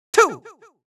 countIn2Farther.wav